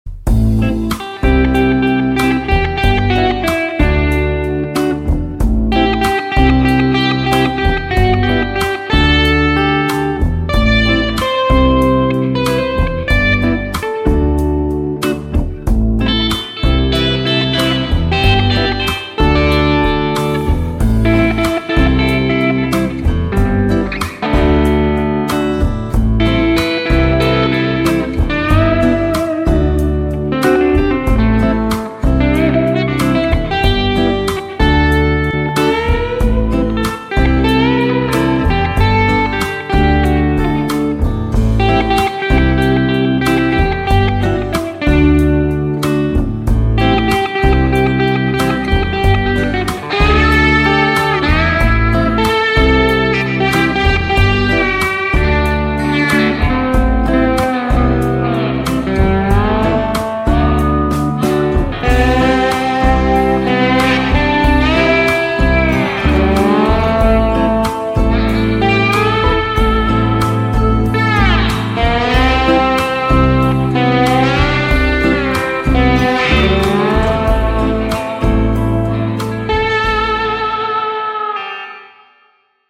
Raahallista mollia..skaba päättyy pe 17.3 klo 22.00
- kun osallistut, soita roots-soolo annetun taustan päälle ja pistä linkki tähän threadiin